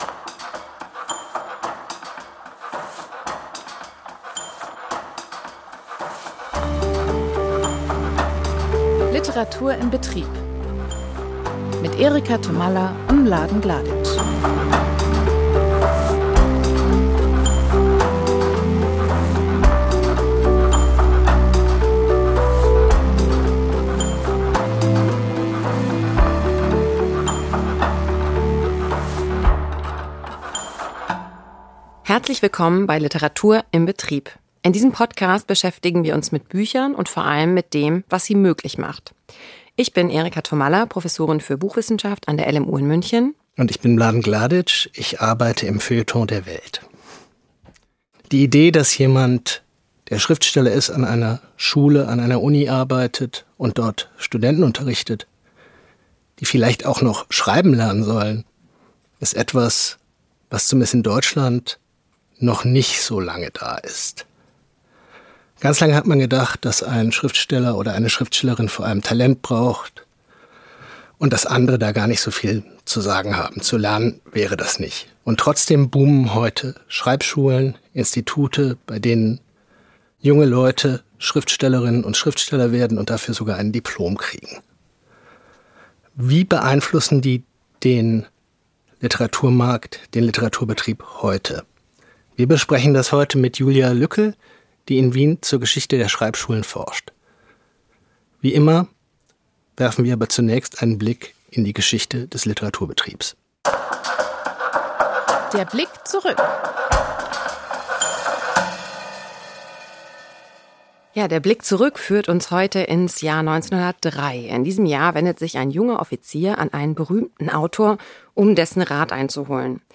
im Gespräch mit der Literaturwissenschaftlerin